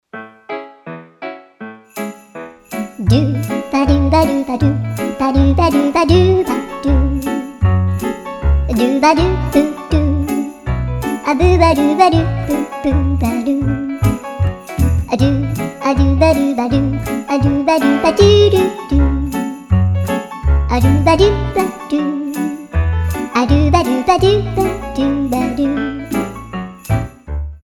веселые , Инструментальные